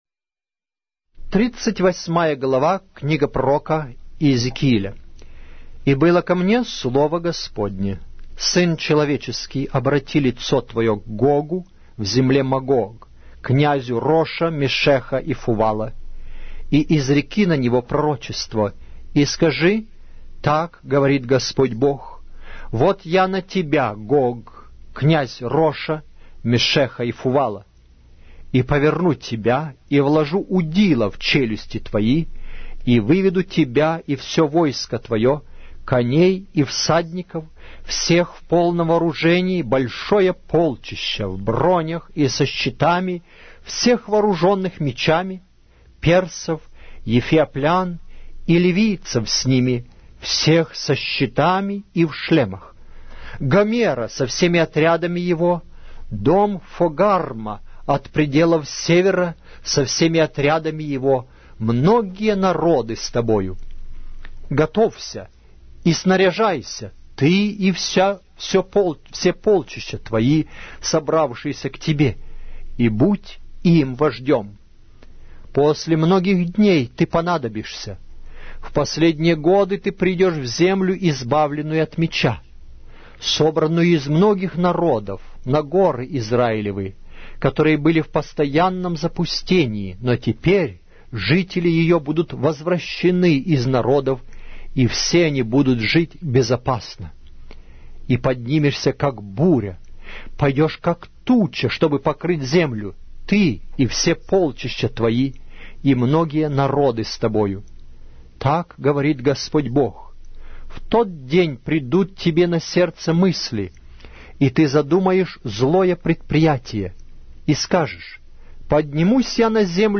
Аудиокнига: Пророк Иезекииль